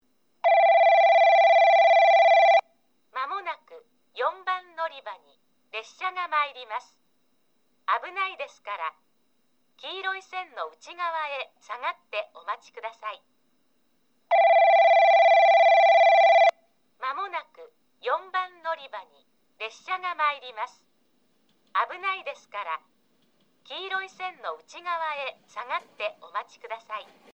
放送は上下とも1，3番のりばが男声、2，4番のりばが女声で固定されています。スピーカーは旧放送同様TOAラッパ型から流れ、クリアホーンからは遠隔放送が流れます。
4番のりば接近放送　女声